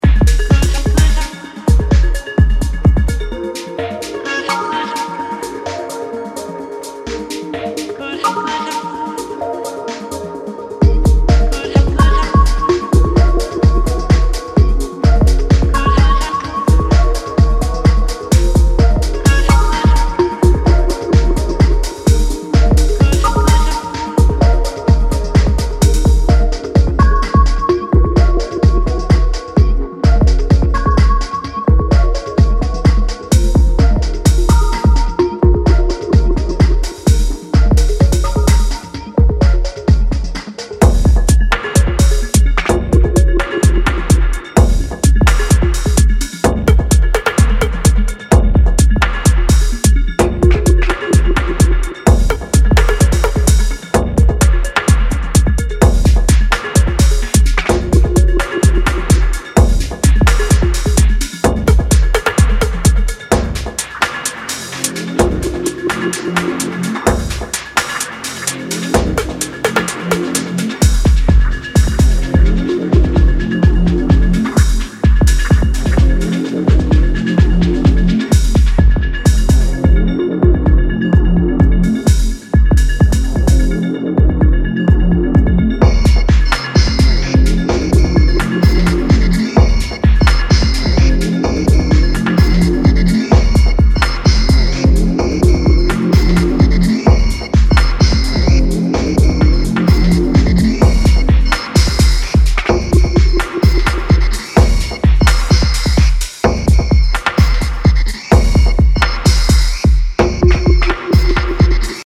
ドリーミーなブロークンビーツ
全編に渡り陽性でポジティブなイメージを伝えてくるメロディーセンスが好ましい。